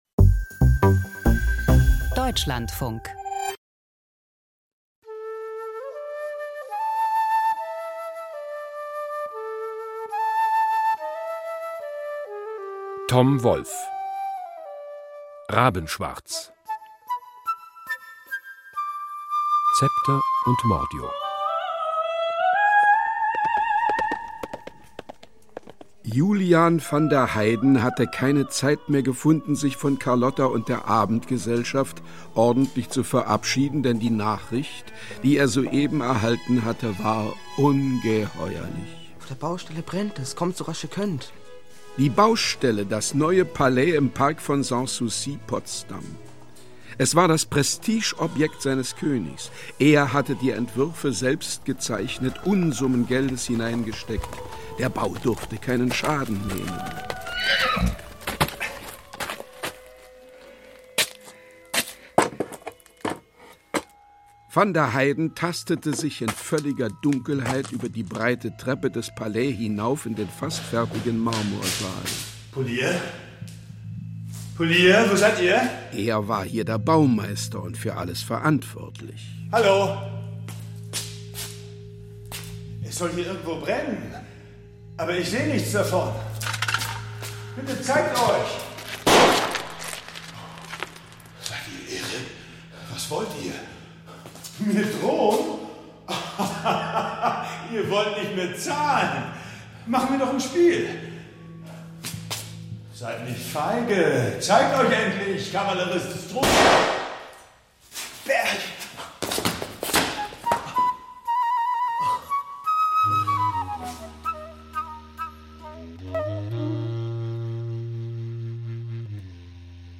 Rabenschwarz – Krimi-Hörspiel von Tom Wolf